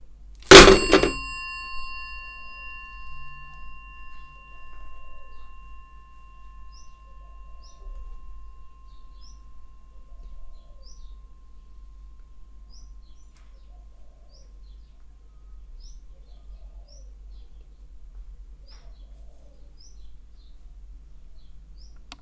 Phone Dong
ding dong free freesound old old-phone phone sound sound effect free sound royalty free Sound Effects